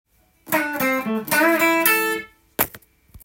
リズムとフレーズTAB譜
フレーズ①
誰もが使いそうな王道フレーズなので